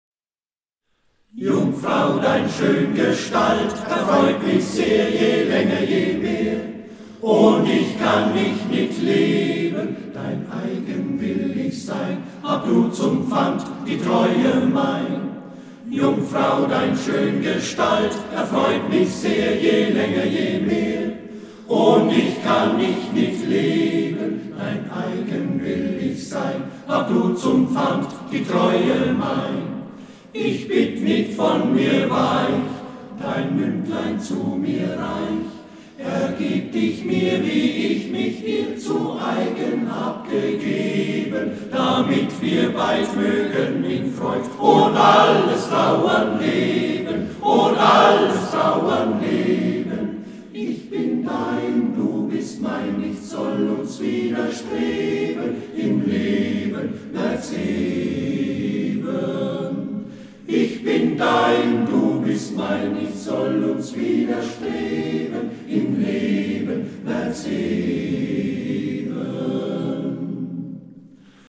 A-capella-Chorgesang